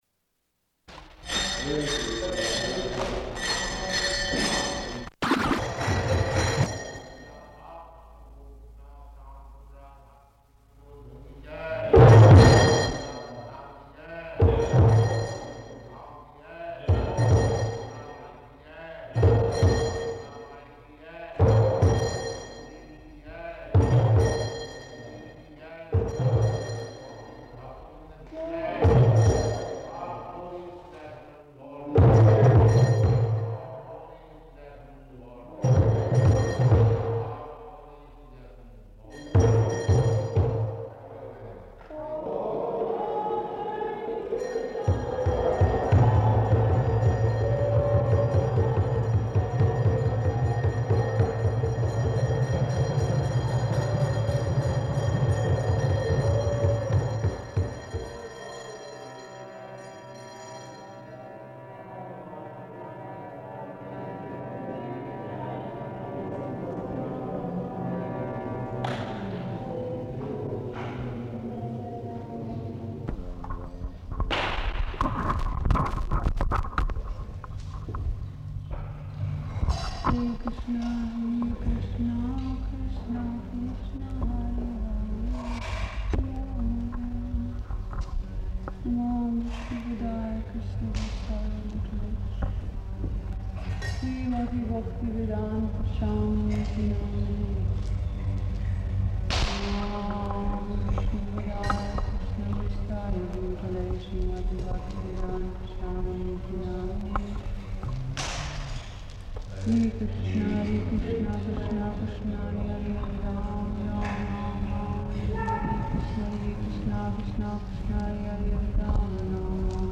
Initiation Lecture
Location: London
[Background conversations and japa] [chants, with devotees responding]